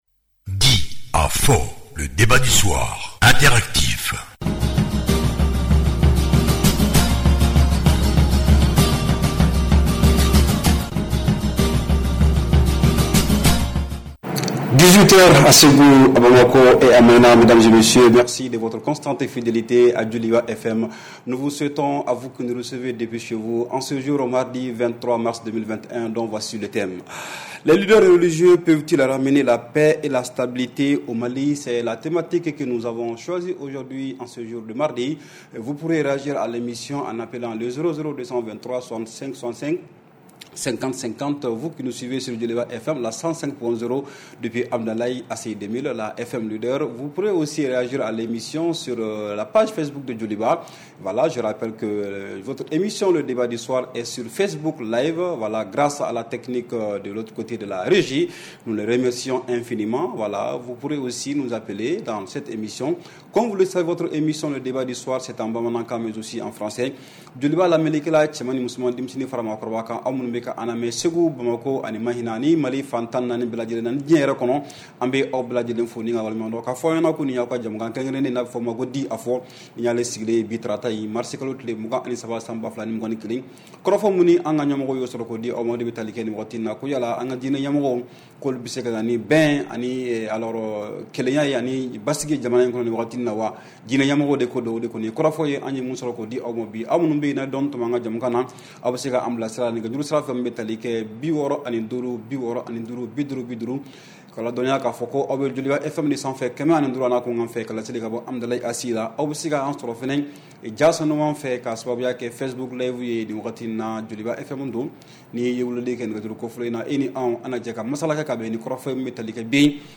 REPLAY 23/03 – « DIS ! » Le Débat Interactif du Soir